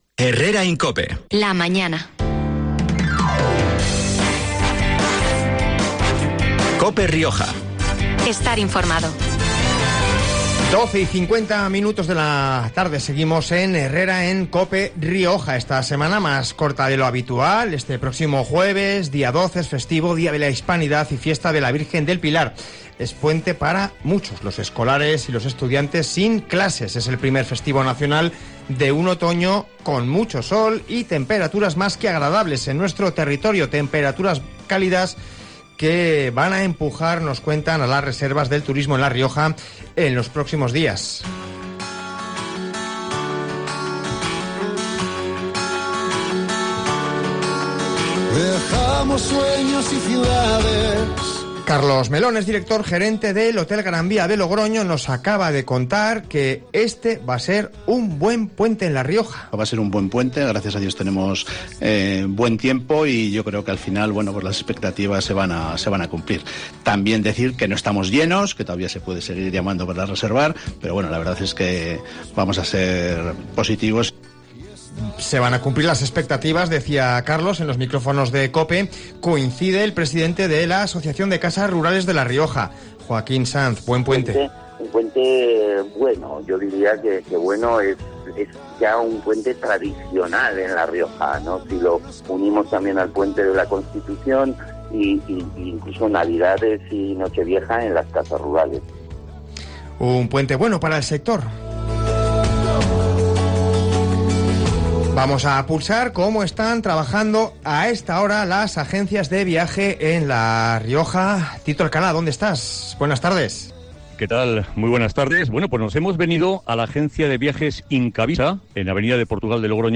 También hemos hablado en el programa de hoy con Diego Bengoa, alcalde de Ezcaray, nombrada “Primera villa turística de La Rioja".